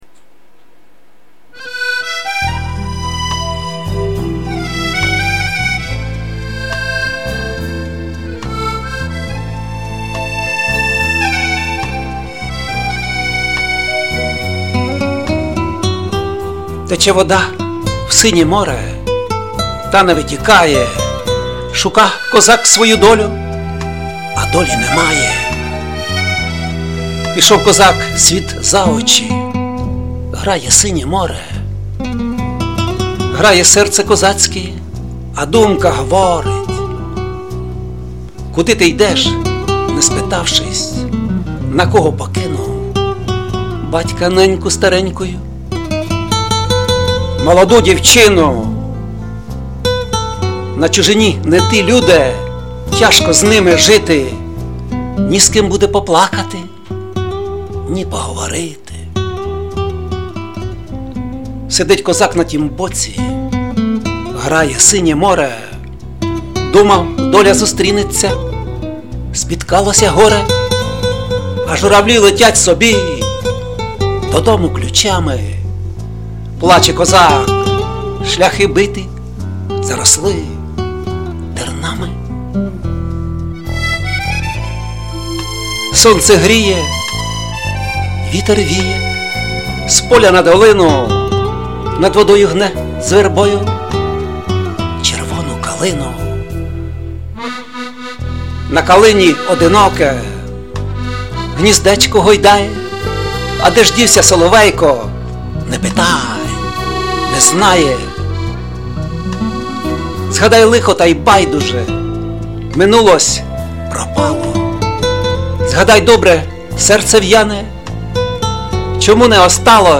А тут повна гармонія: зміст поезії,підсилює філософське звучання музики, органічно вплітається своєрідне авторське виконання.